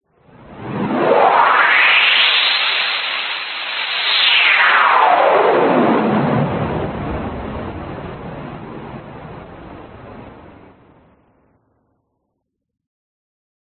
唰唰的声音 fx
描述：风声的一种音效